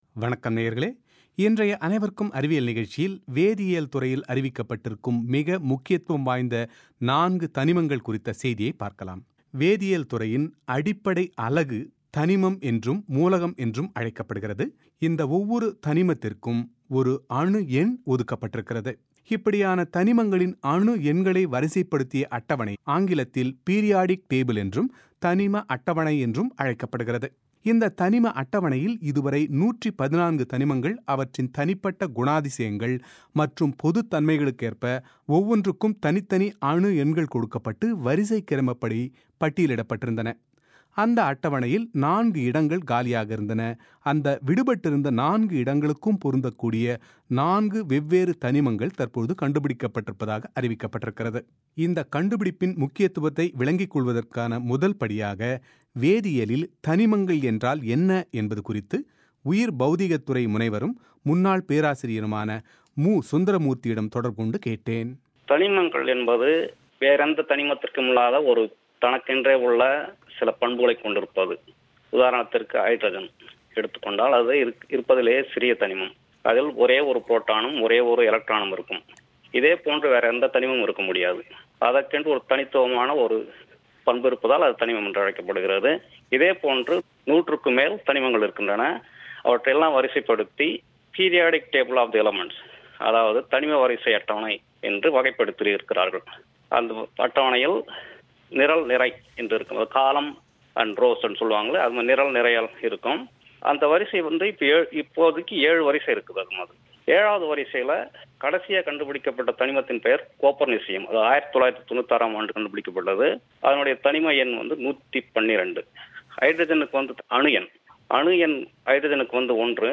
அவரது செவ்வியின் விரிவான ஒலி வடிவத்தை நேயர்கள் இங்கே கேட்கலாம்.